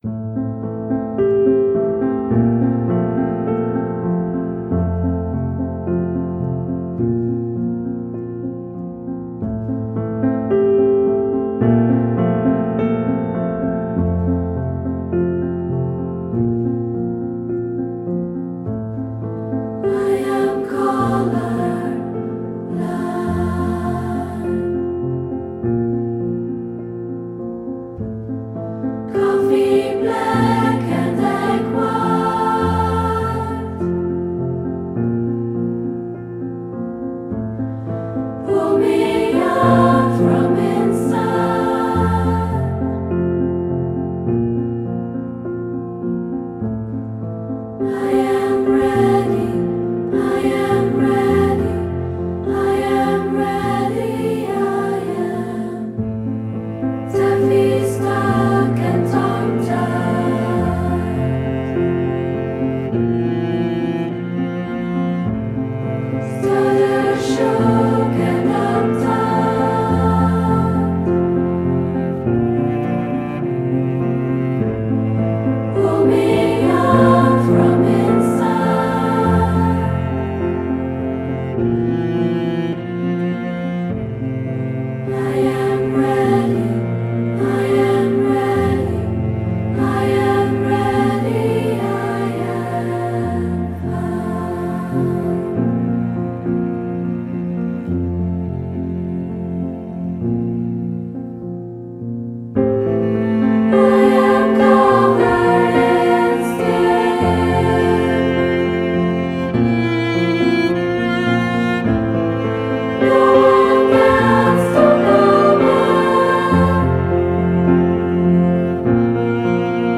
Belgium all girls choir